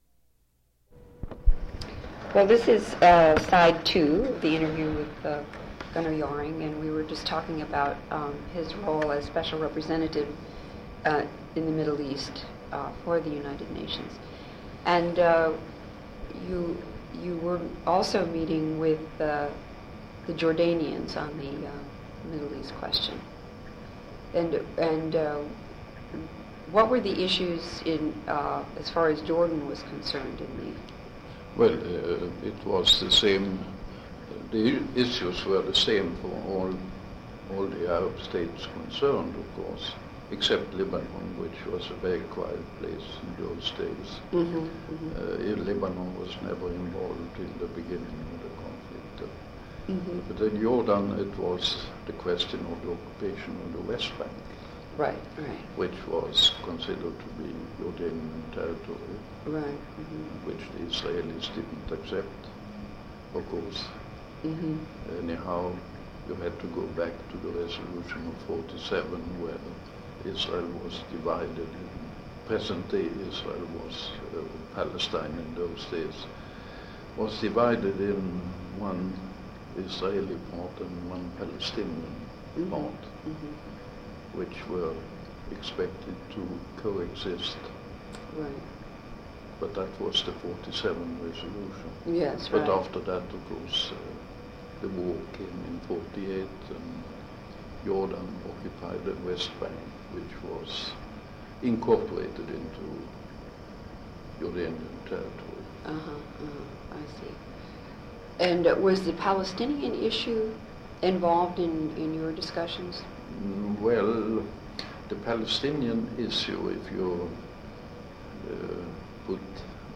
Interview with Gunnar Jarring